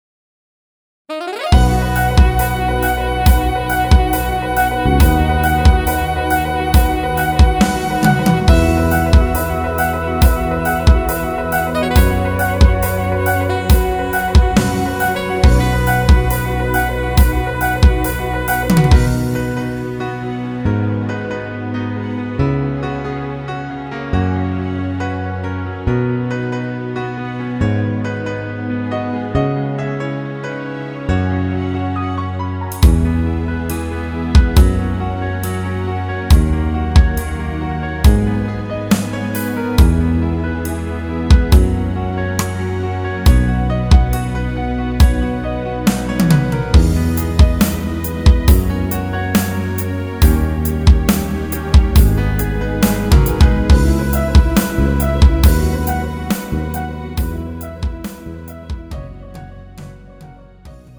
음정 (-2)
장르 가요 구분 Lite MR